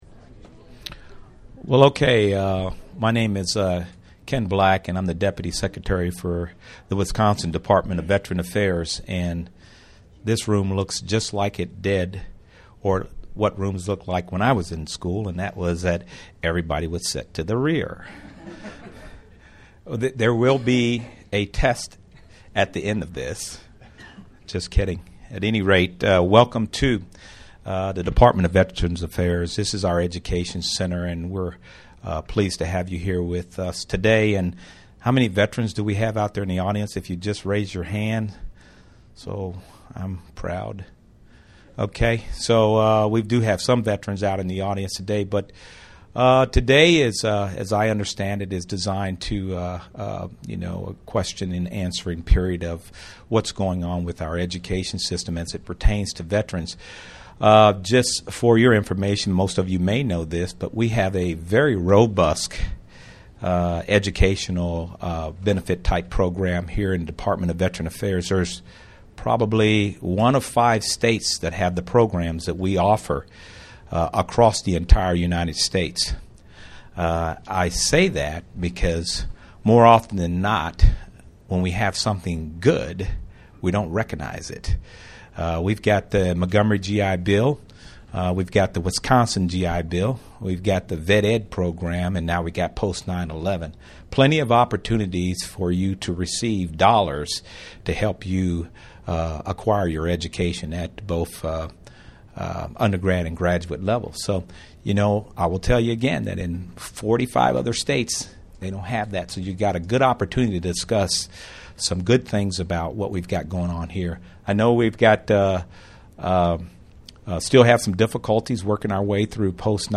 This potential influx of students has prompted colleges and universities to rethink current policies and procedures in order to help better serve the unique needs of veterans. In this brown bag forum, a panel of representatives from three Madison campuses?each with differing missions and services?will describe their institutions? responses to the needs of student veterans.